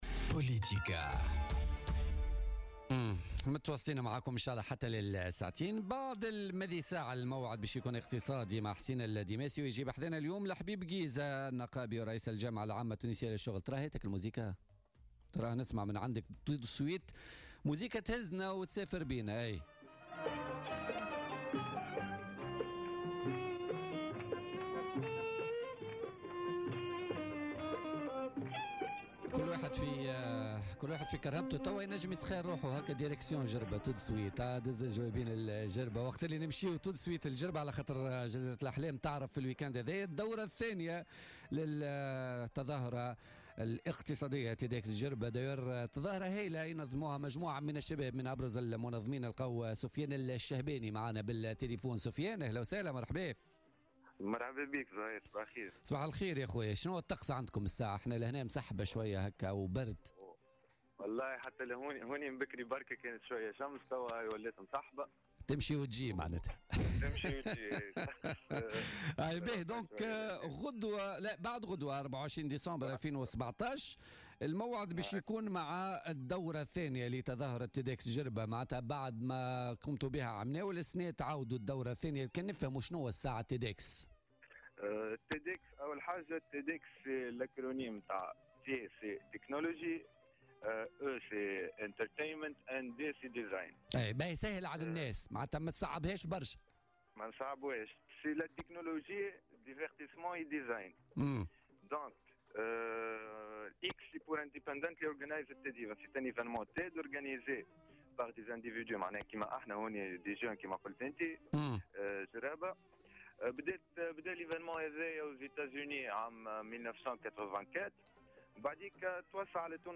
مداخلة له في بوليتكا